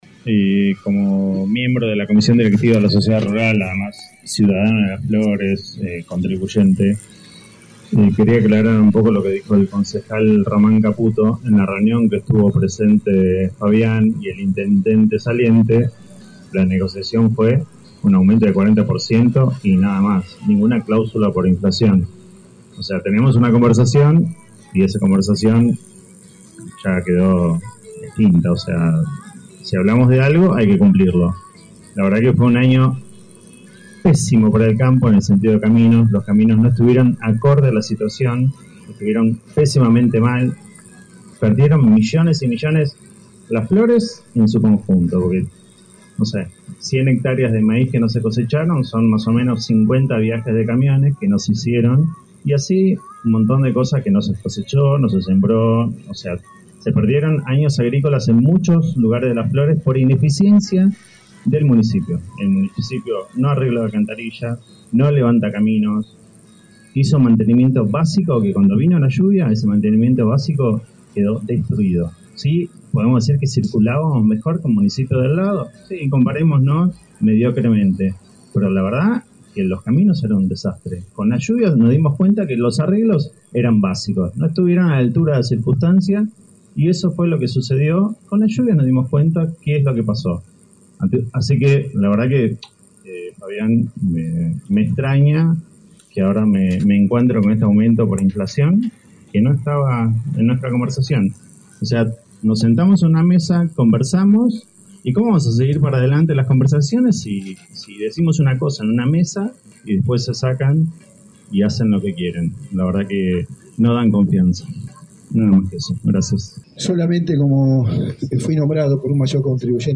Asamblea de Mayores Contribuyentes: el campo atacó al Ejecutivo y el oficialismo respondió